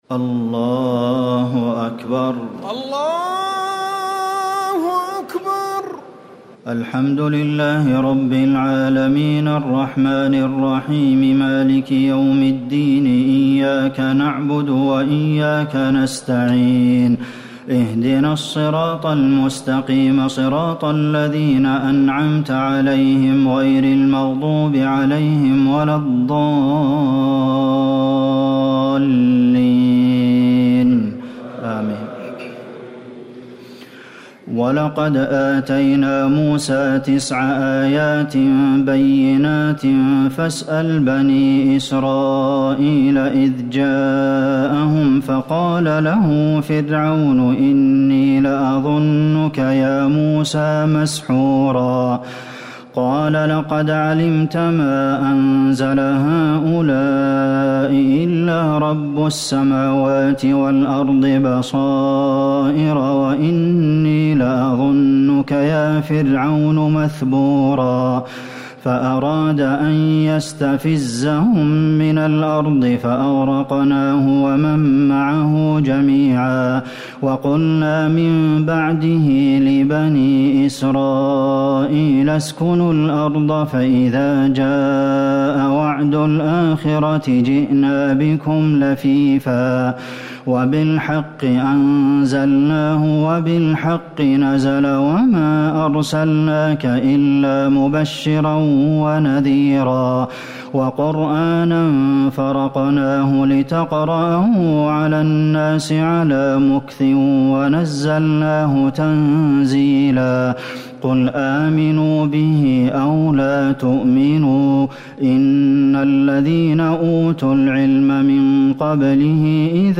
تراويح الليلة الرابعة عشر رمضان 1439هـ من سورتي الإسراء (101-111) والكهف (1-59) Taraweeh 14 st night Ramadan 1439H from Surah Al-Israa and Al-Kahf > تراويح الحرم النبوي عام 1439 🕌 > التراويح - تلاوات الحرمين